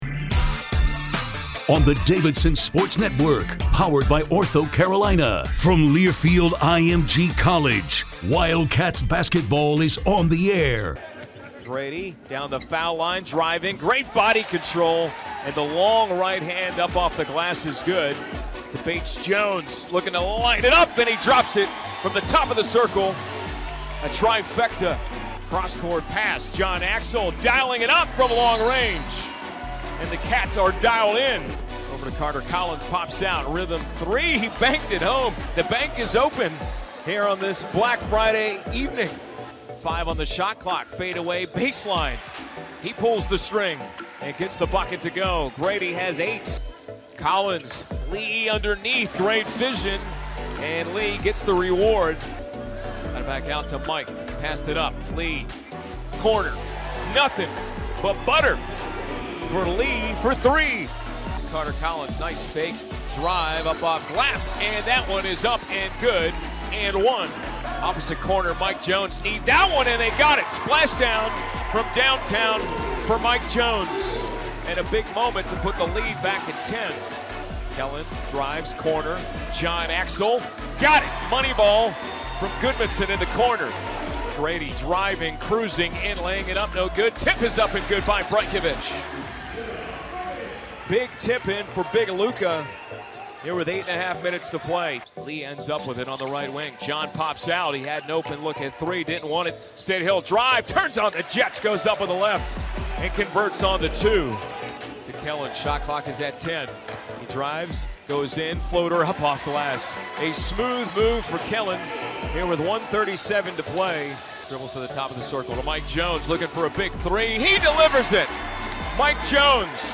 Radio Highlights
Fairfield vs. Davidson Highlights.mp3